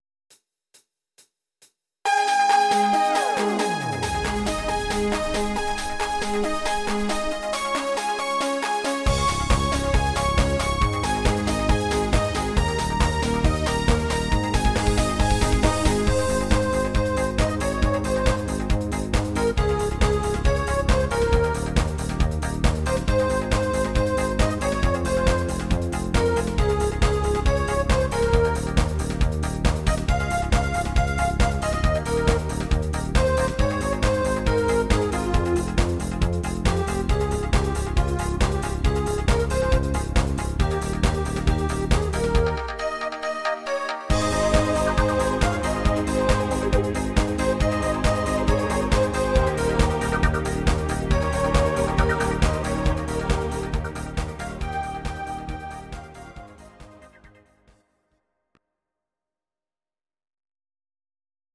Audio Recordings based on Midi-files
Pop, German, 2000s